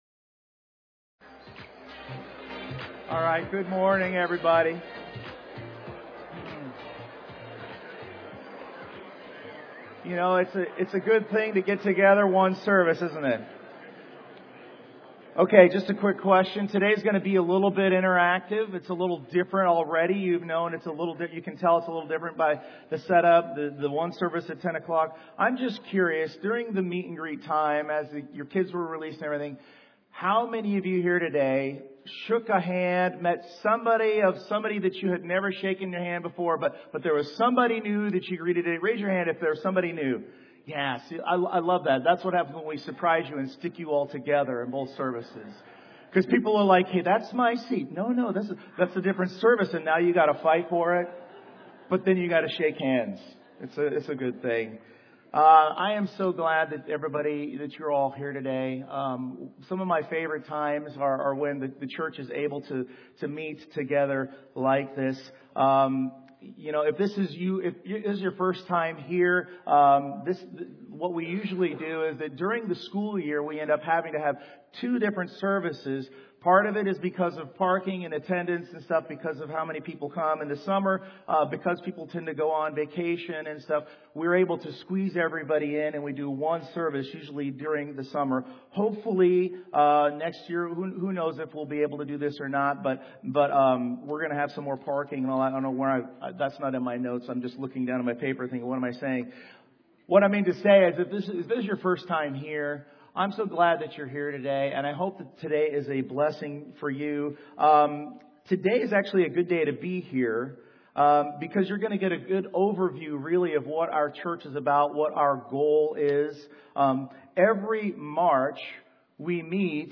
2026 Sermons Passage